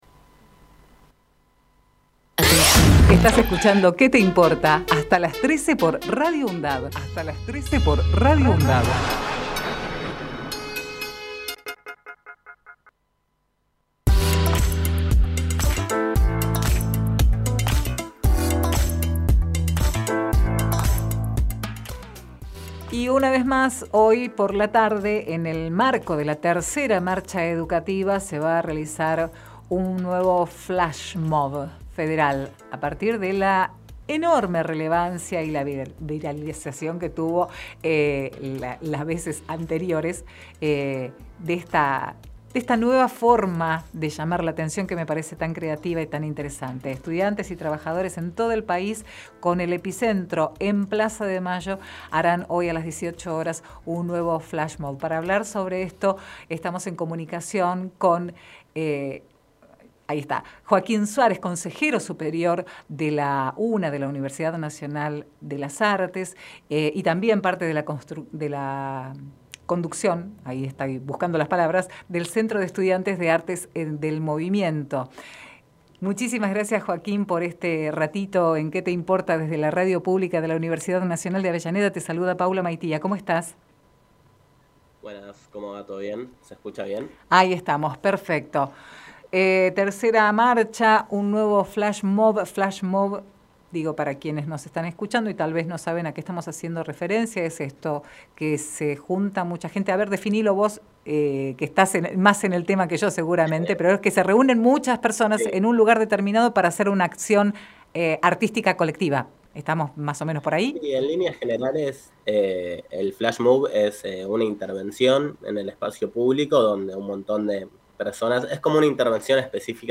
Compartimos la entrevista realizada en "Que te importa?!"